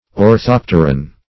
Orthopteran \Or*thop"ter*an\, n. (Zool.)